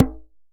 DJEM.HIT12.wav